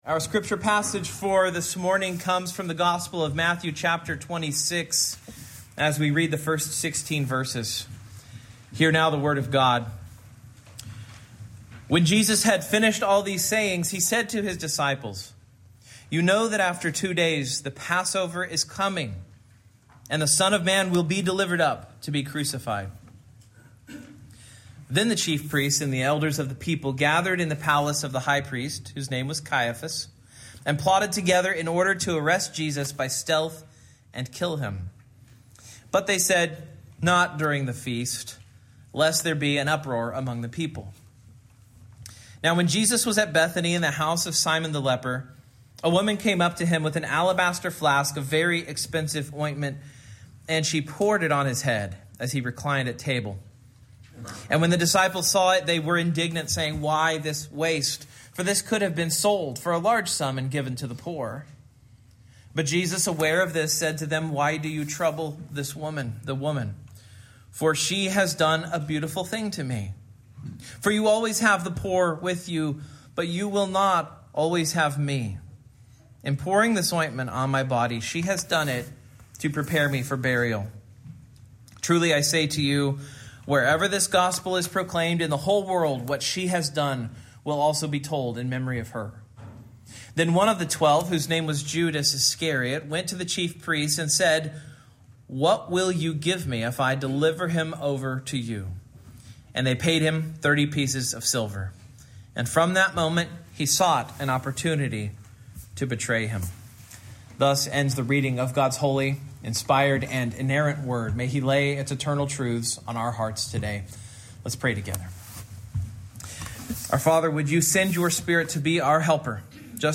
Preacher
Service Type: Morning